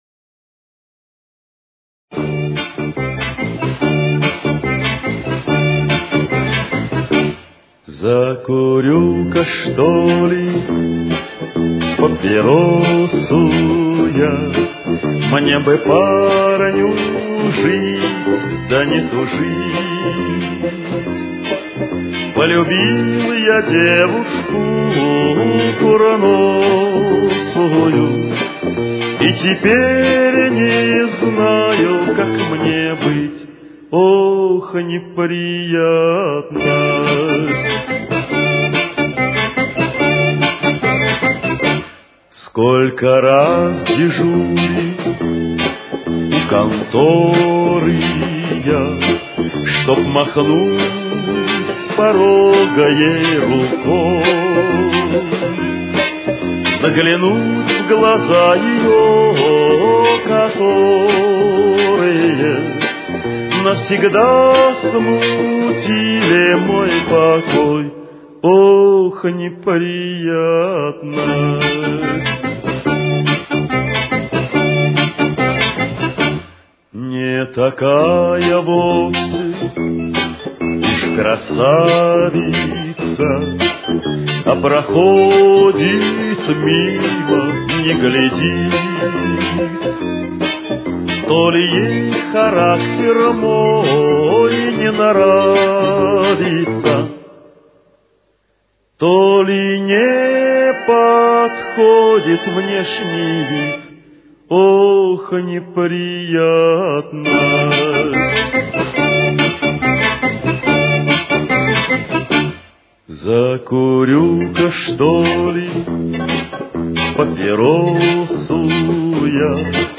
Темп: 71.